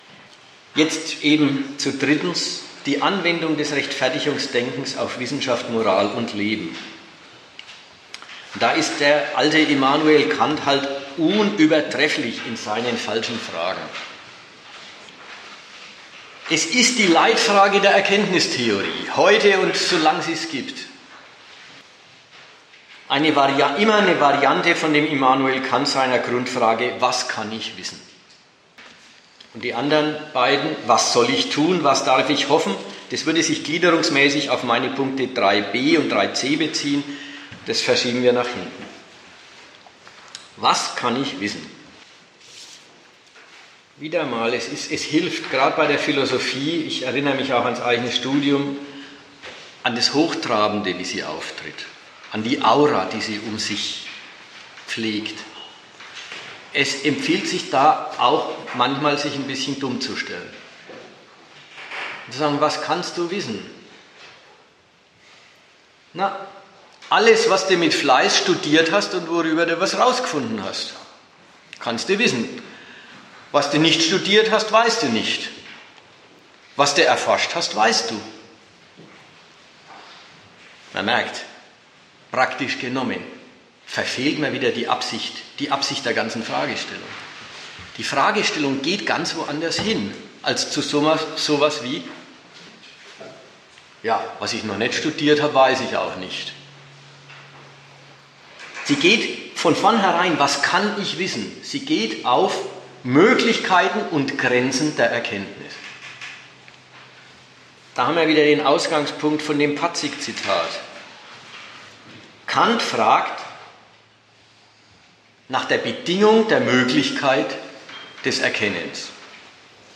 Der Vortrag bei der Sozialistischen Gruppe ist hier.